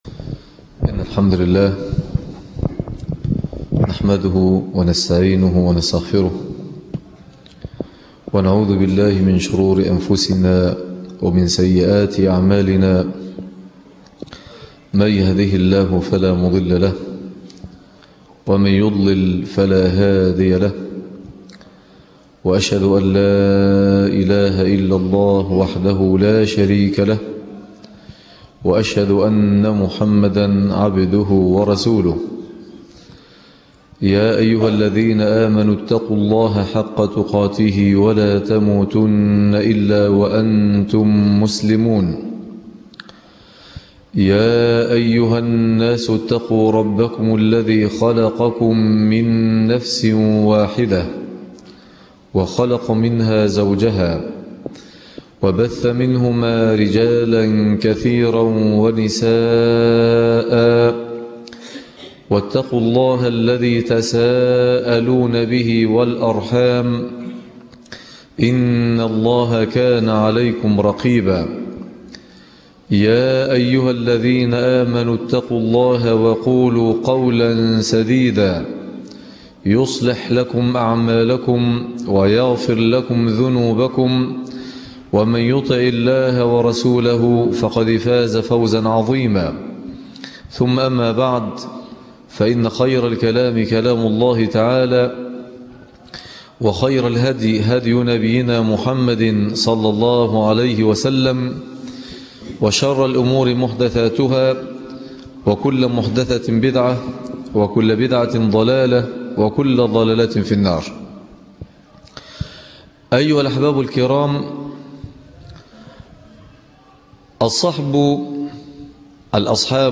أهميـة الصحبـة الصالحـة - خطب الجمعة